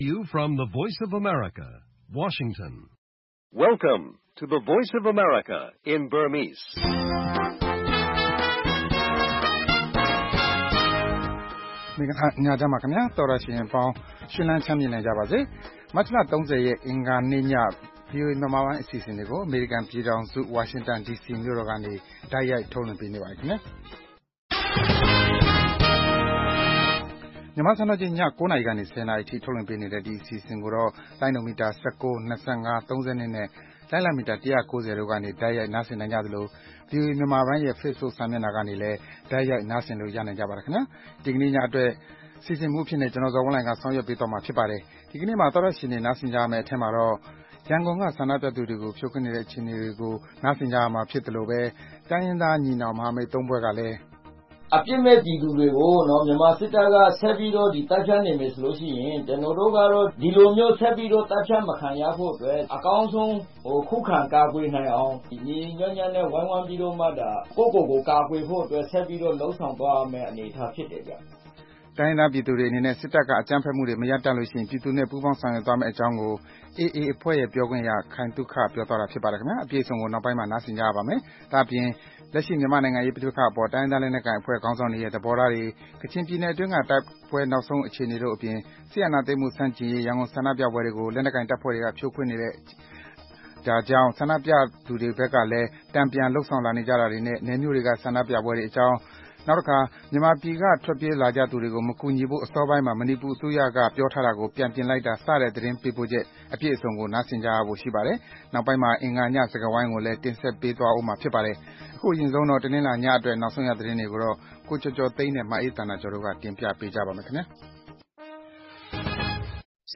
သီတင်းပတ်စဉ် - အင်္ဂါနေ့ညစကားဝိုင်းအစီအစဉ်။